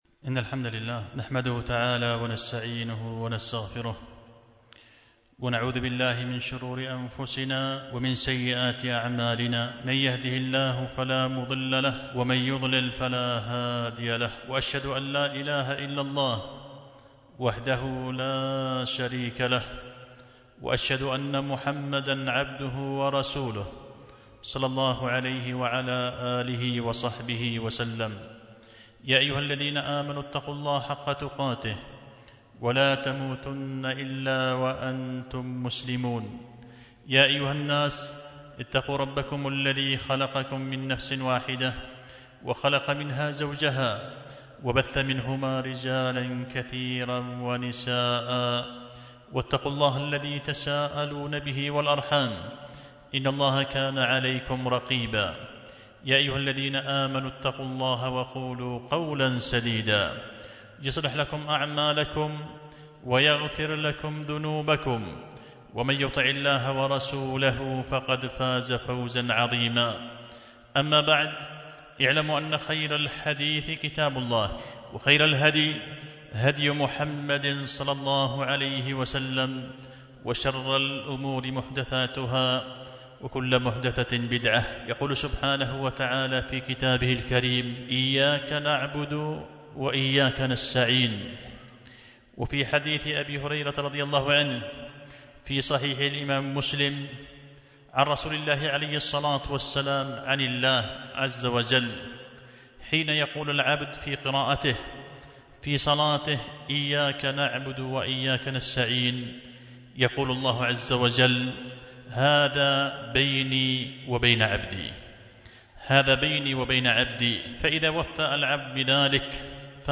خطبة جمعة بعنوان مفتاح السرور في الحث على التسمية لرفع أو دفع الشرور